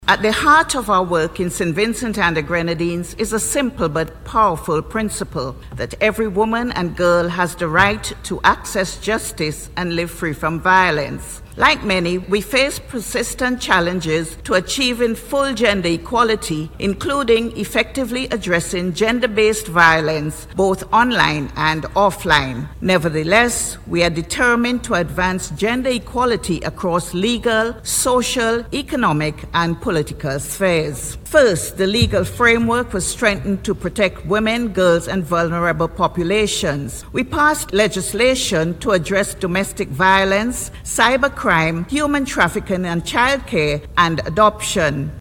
She made the point during the 5th plenary meeting of the Seventieth session of the Commission on the Status of Women (CSW70) which is taking place from March 9th to 19th 2026 at United Nations Headquarters in New York.